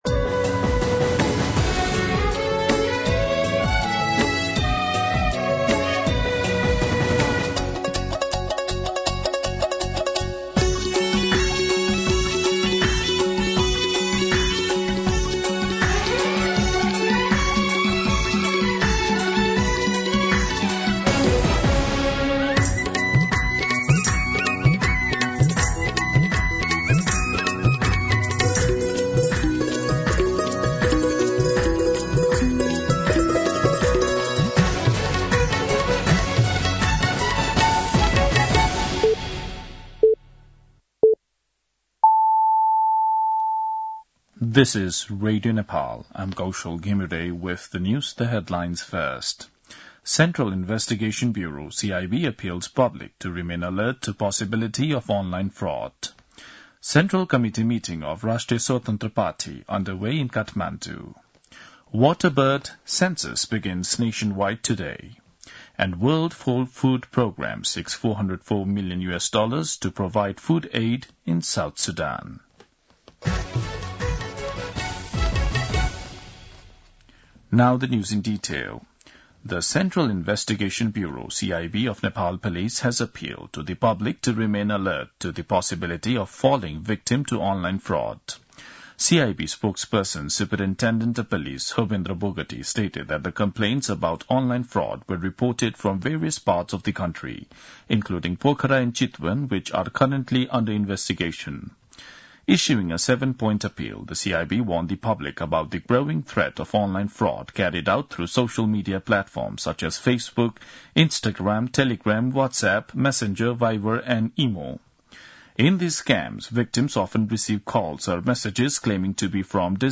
दिउँसो २ बजेको अङ्ग्रेजी समाचार : २१ पुष , २०८१
2-pm-English-News.mp3